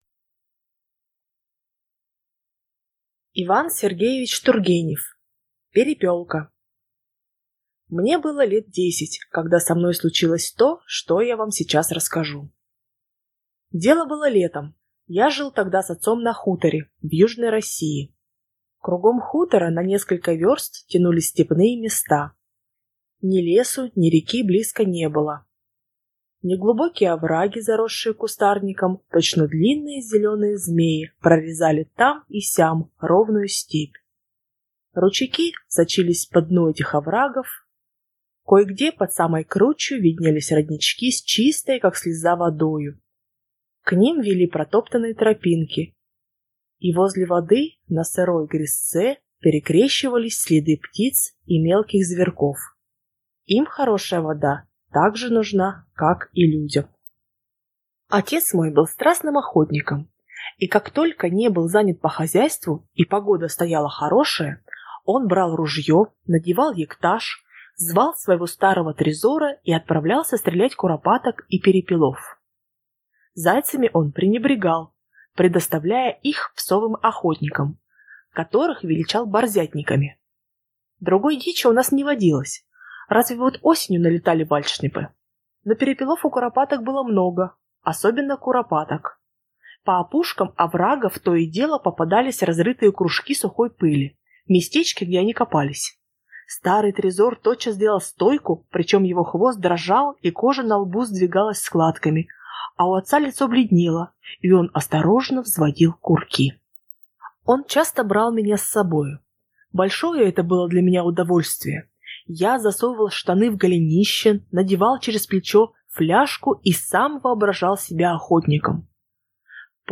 Аудиокнига Перепёлка | Библиотека аудиокниг